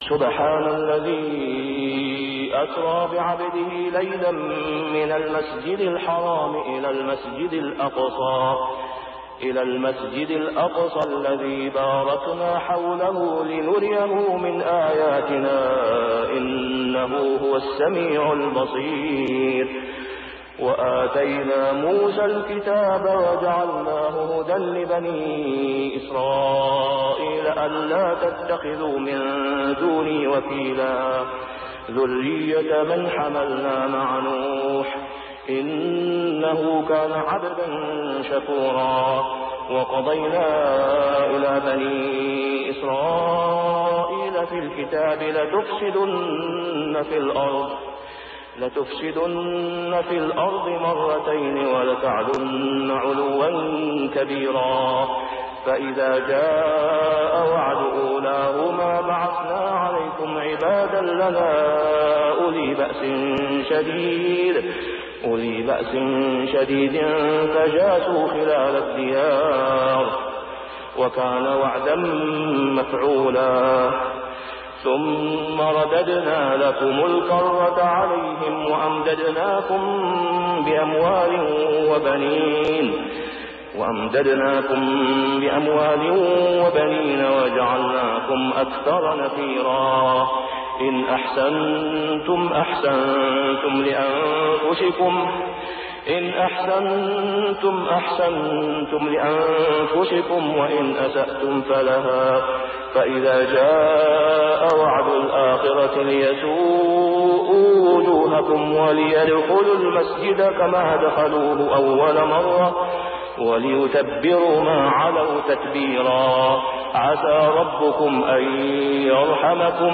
صلاة الفجر عام 1423هـ | فواتح سورة الإسراء 1-39 | > 1423 🕋 > الفروض - تلاوات الحرمين